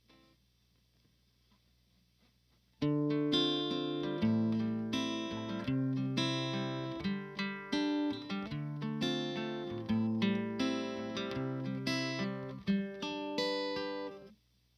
Guitar_Sadness_85bpm_ Dm
Guitar_Sadness_85bpm_-Dm.wav